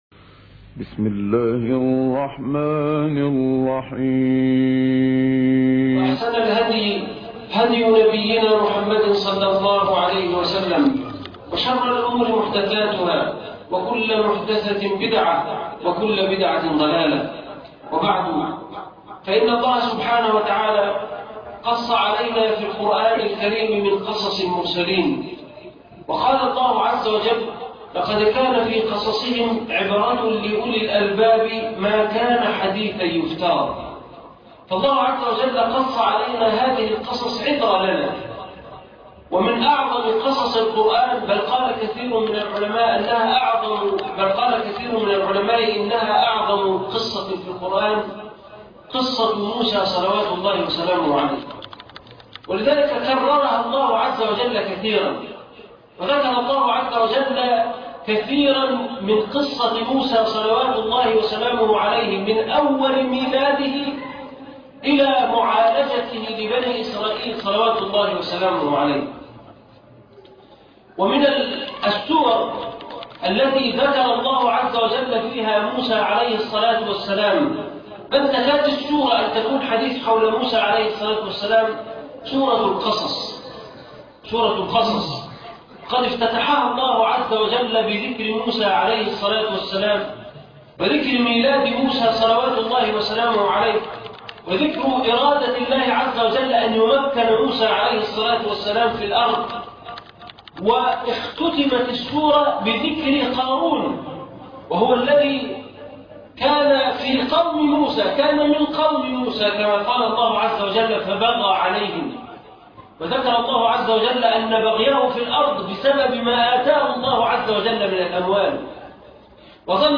قصة سيدنا موسى _ خطبة جمعة